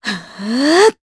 Juno-Vox_Casting1_jp.wav